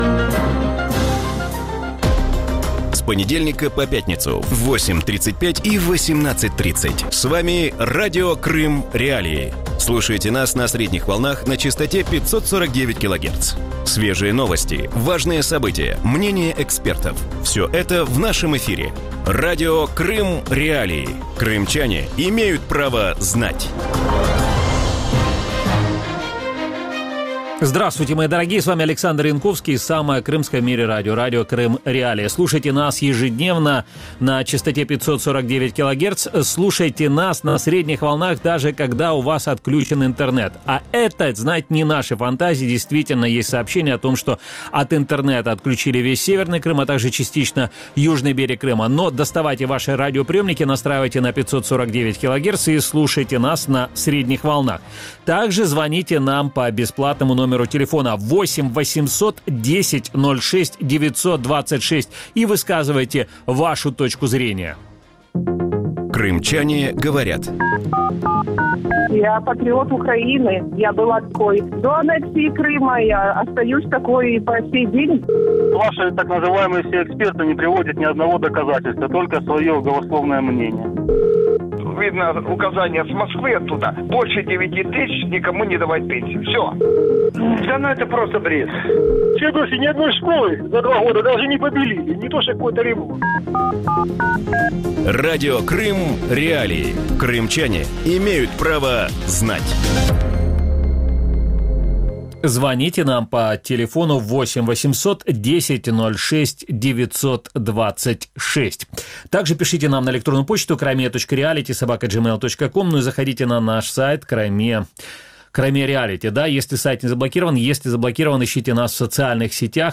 В вечернем эфире Радио Крым.Реалии говорят о Международном дне коренных народов мира.